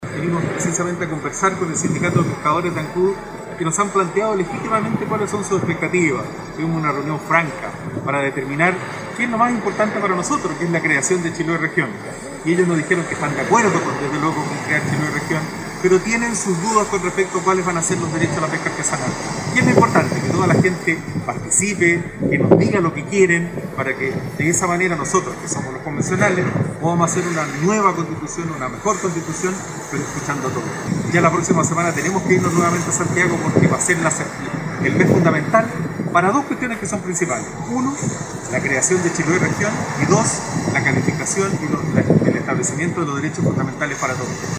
El constituyente Julio Álvarez manifestó que las inquietudes del sector pescador artesanal son bienvenidas, pues lo fundamental es que estén todas las posiciones representadas.